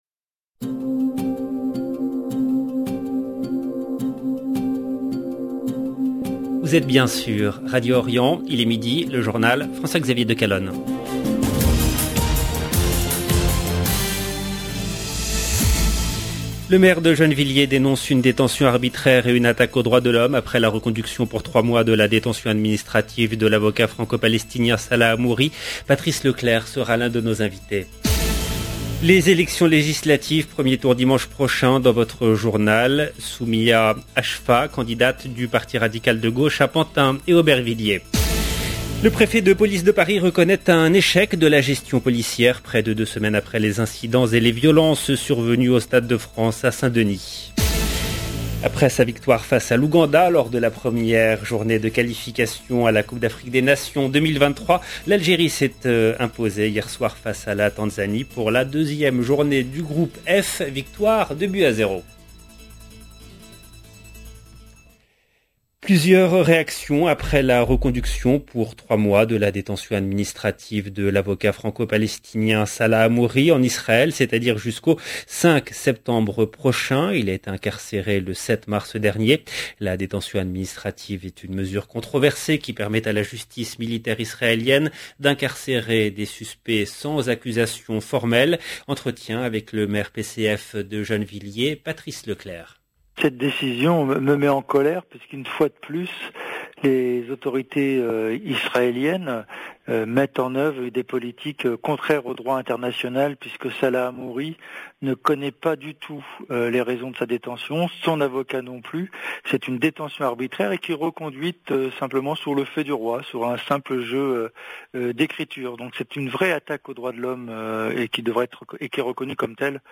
LE JOURNAL EN LANGUE ARABE DE MIDI 30 DU 9/06/22